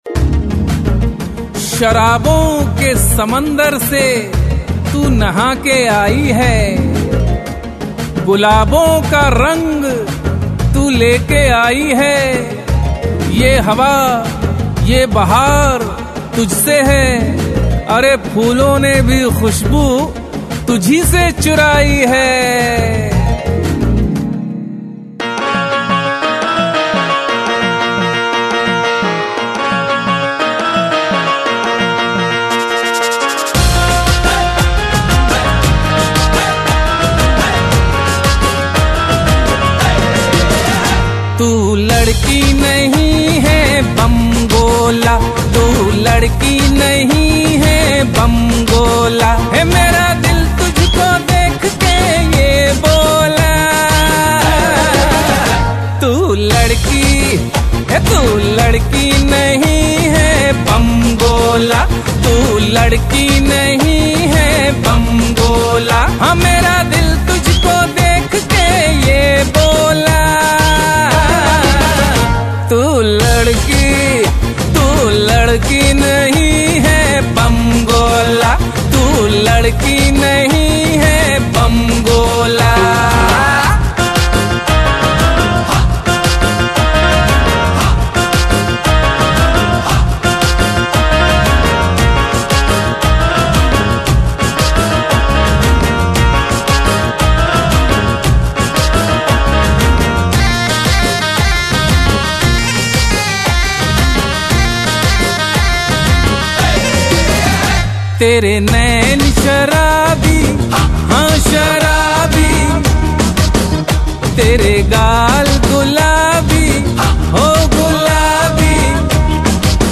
Single Pop Songs